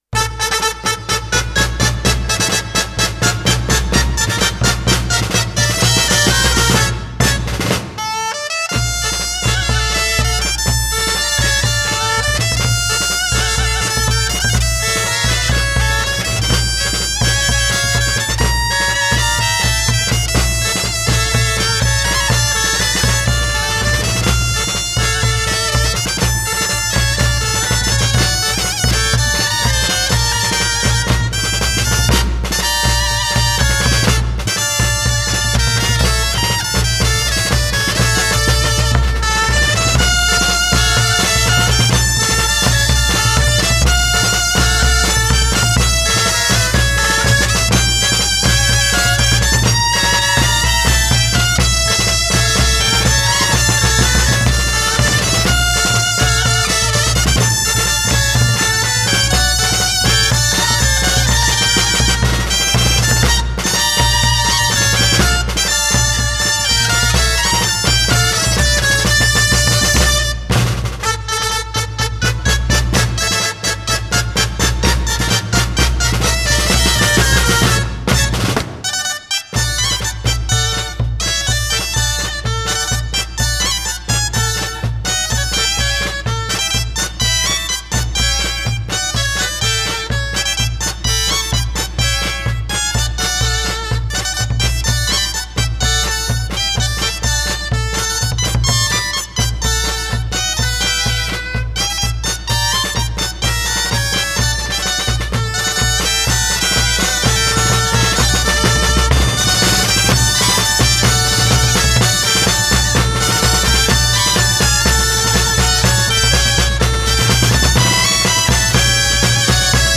Dolçainers
Santa Tecla Tarragona